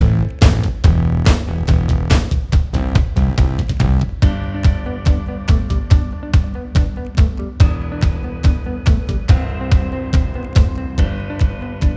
Minus All Guitars Pop (2010s) 3:35 Buy £1.50